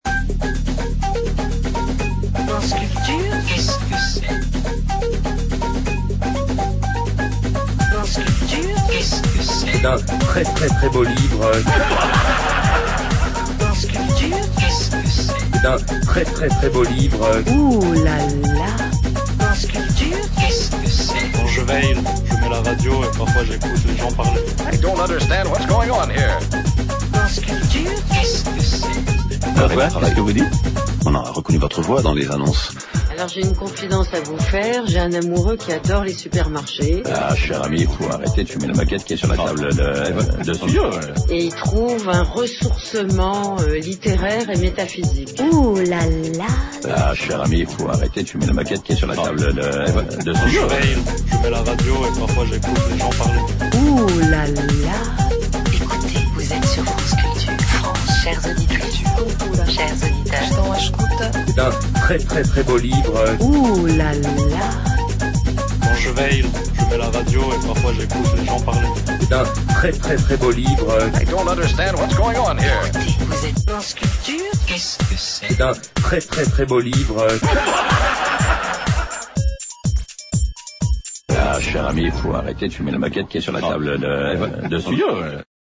Cavada himself!